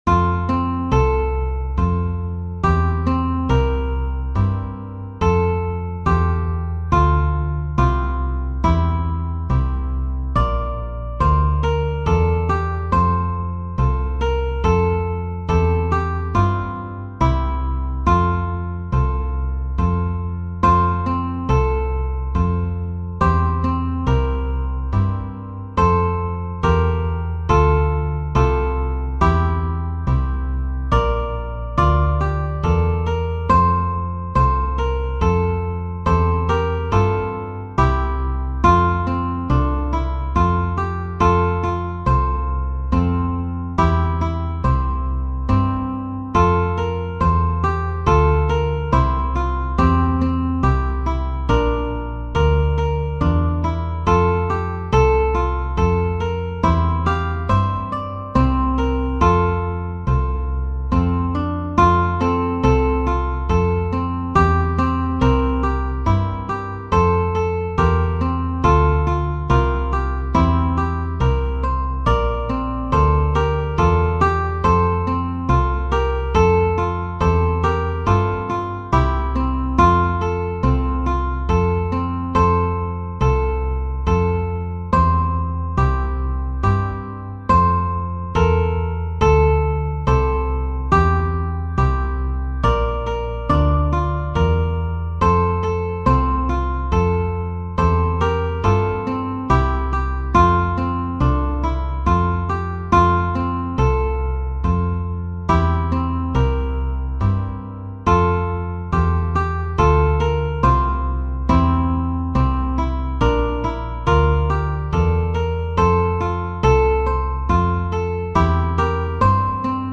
Dona Nobis Pacem - Eynsham Ukulele Quartet
Uke 1
Uke 2
Rhythm Uke
Bass